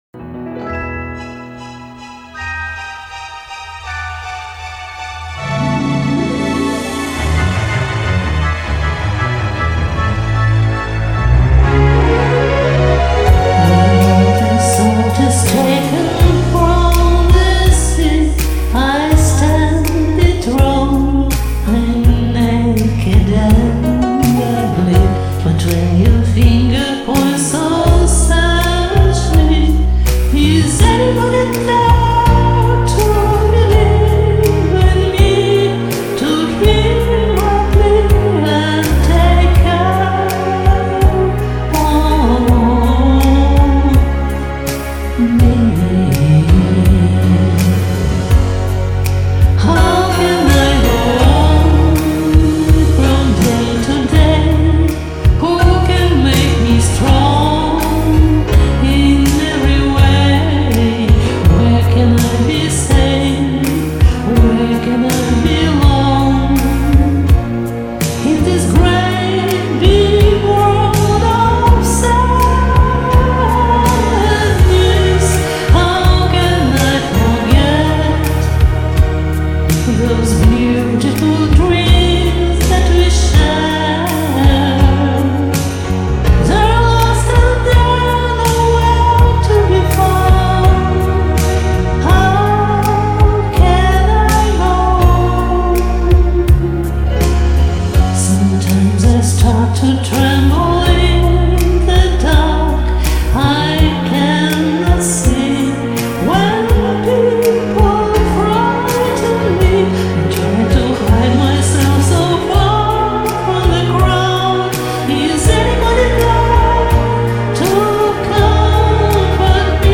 голос зажат и скрипуч